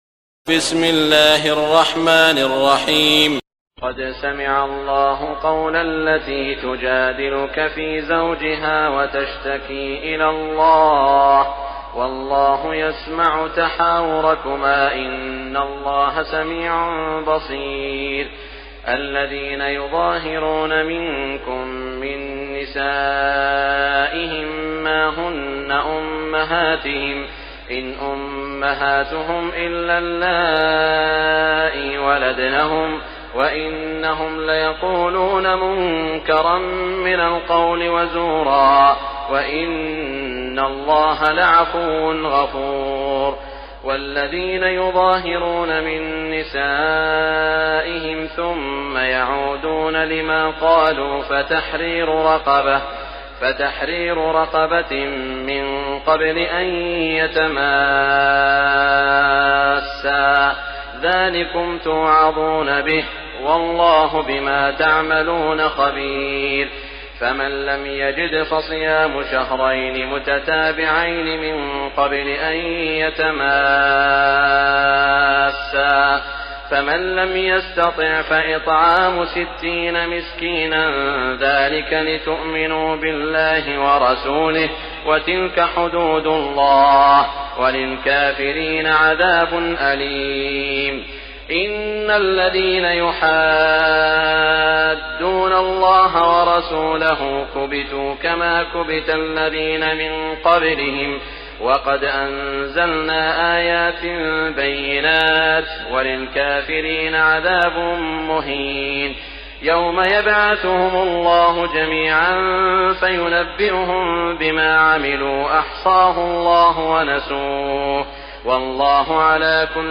تراويح ليلة 27 رمضان 1418هـ من سورة المجادلة الى الصف Taraweeh 27 st night Ramadan 1418H from Surah Al-Mujaadila to As-Saff > تراويح الحرم المكي عام 1418 🕋 > التراويح - تلاوات الحرمين